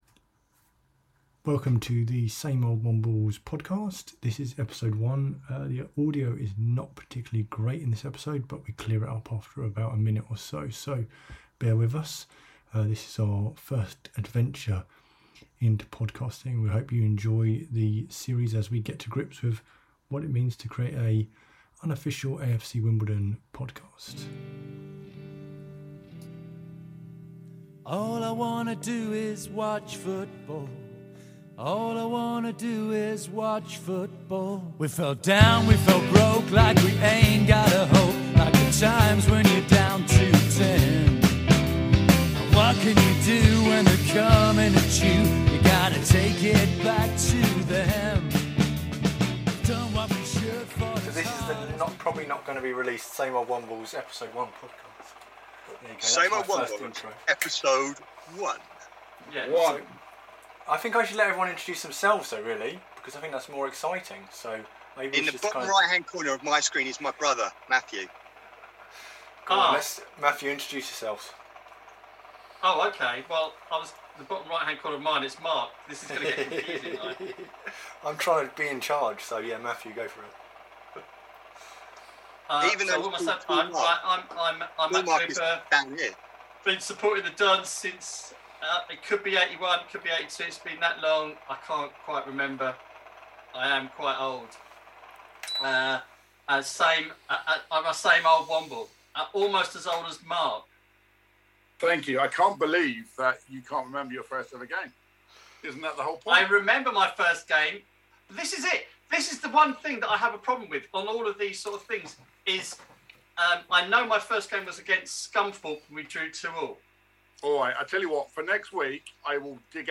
Brand new unofficial AFC Wimbledon Football (Soccer) podcast has kicked off! Same Old Wombles a show with 4-6 presenters talking Wimbledon!